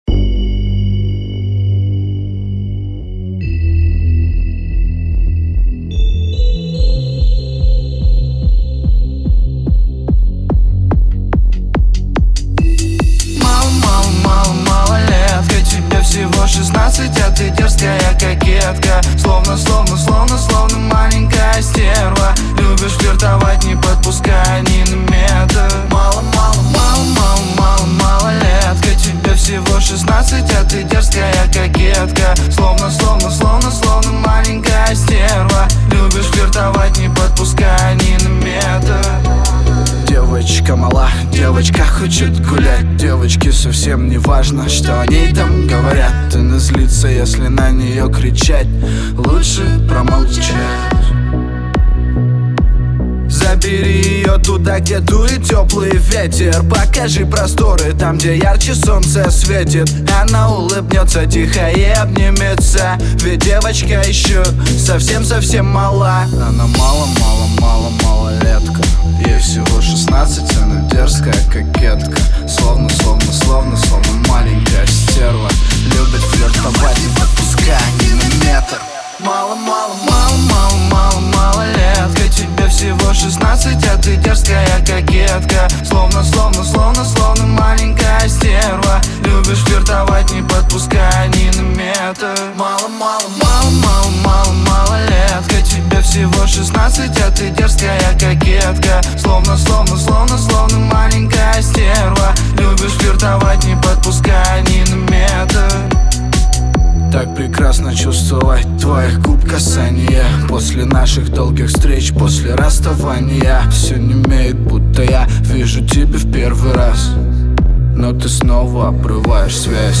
Настроение трека — легкое и игривое, с нотками ностальгии.
динамичными гитарными рифами и запоминающимся припевом